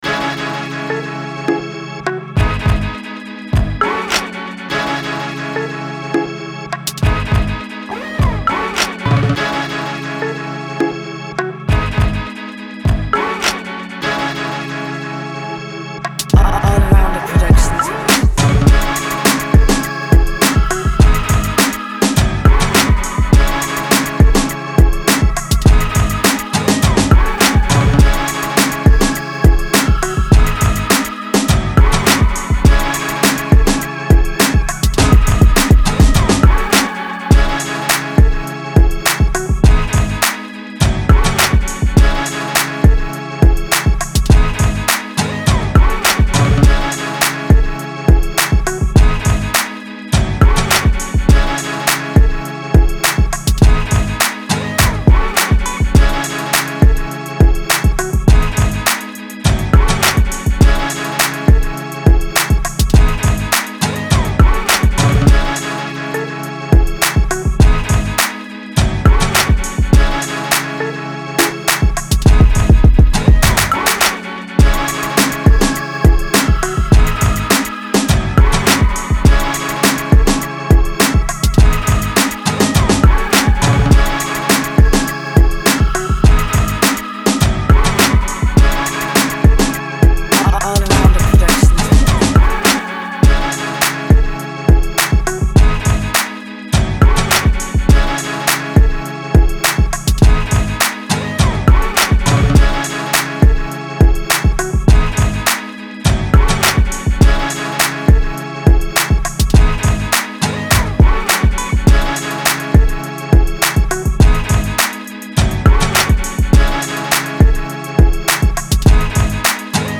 ловите лучшие 10 минусов, пряных, качающих, сочных.